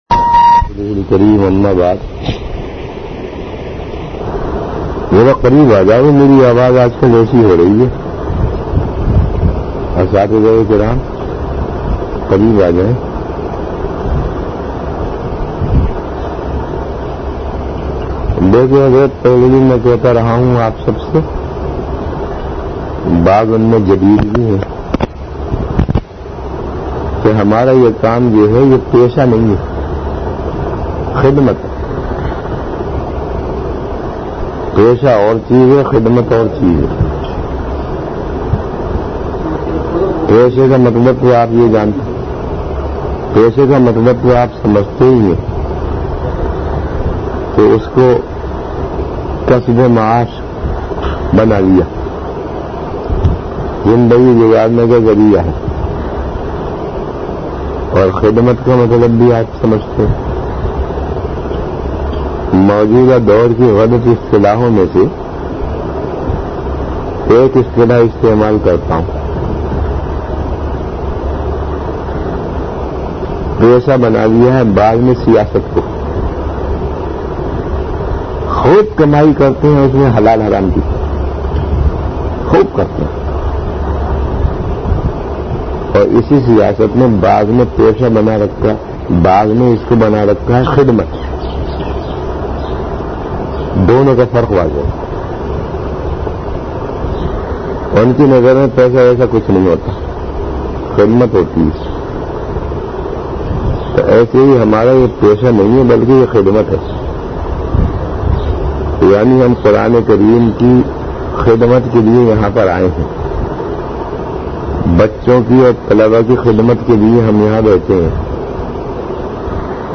An Islamic audio bayan
Delivered at Jamia Masjid Bait-ul-Mukkaram, Karachi.